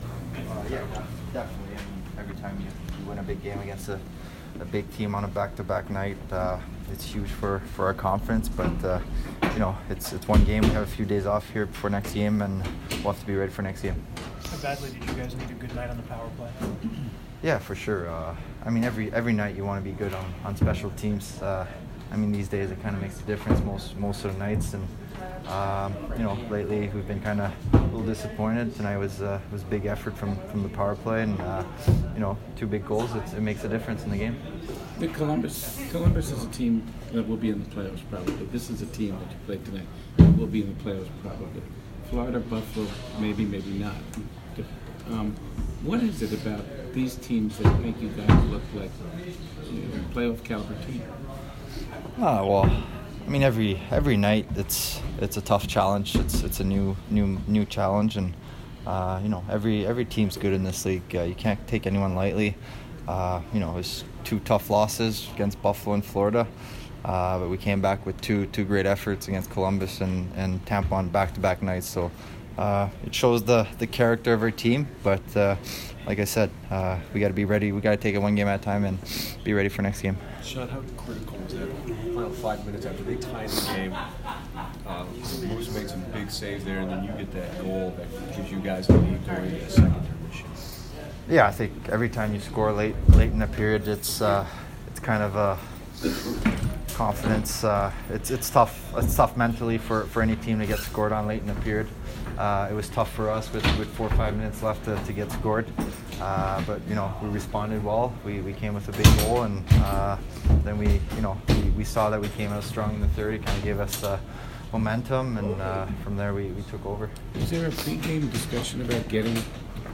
Sean Couturier Post-Game 12/29